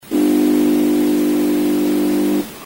Question forum dépannage climatisation : Bruit dans console climatisation
Je m’explique, lorsque, je mets en fonction toutes les 3 à 4 minutes quelques consoles font un bruit (une vibration) qui dure 2 secondes.
Je vous joins un fichier mp3, il a une durée de 2s, juste le temps de ce fameux bruit.
En tous cas, c'est du 50 Hz (ou 100 à la rigueur), donc causé par quelque chose qui marche sur le secteur.
bruit.mp3